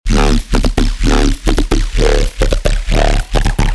BASS HOUSE PRESETS
Basses
Funky, yet hard hitting! Seperate from our ‘wub’ presets, these basses power your tunes and keep the energy pumping!